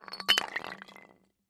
Пустая бутылочка из-под яда выскользнула из рук